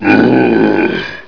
assets/psp/nzportable/nzp/sounds/zombie/w0.wav at 145f4da59132e10dabb747fa6c2e3042c62b68ff